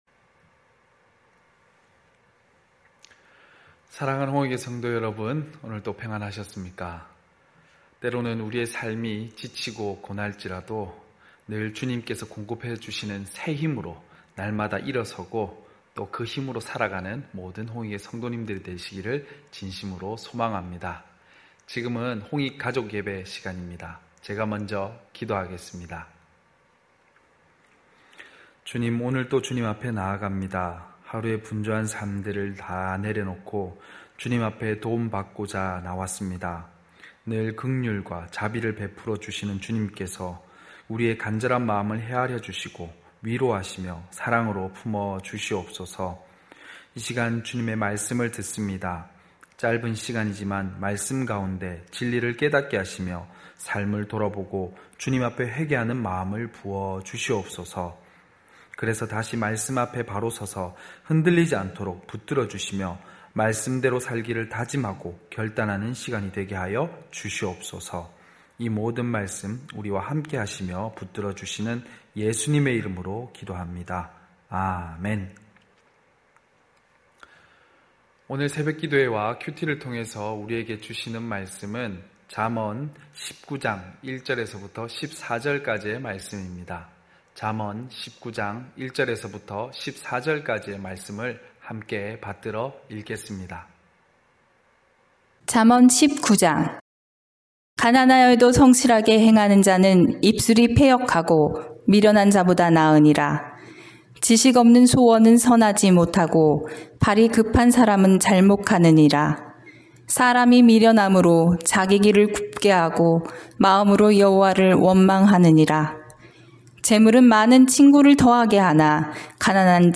9시홍익가족예배(6월5일).mp3